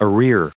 added pronounciation and merriam webster audio
313_arrears.ogg